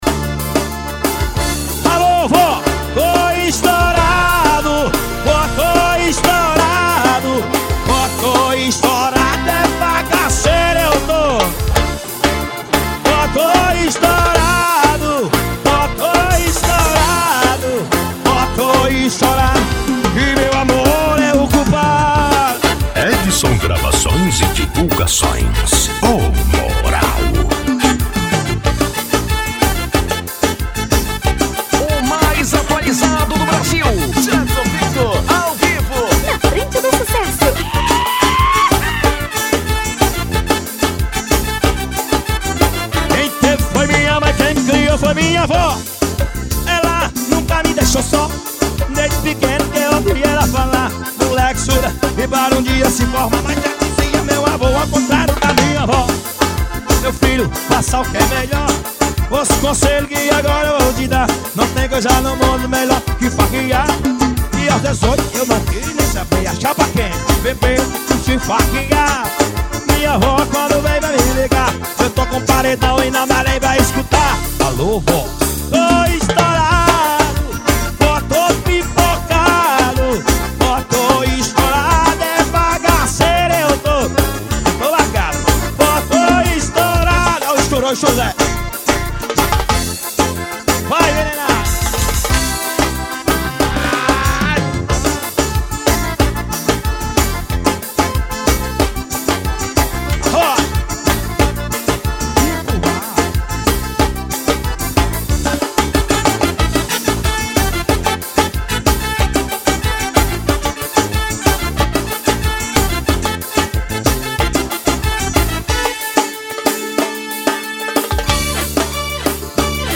forro.